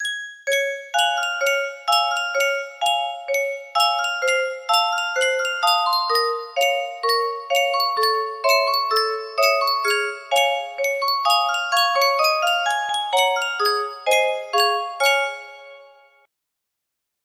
Yunsheng Music Box - BKTYWFF Part 2 5227 music box melody
Full range 60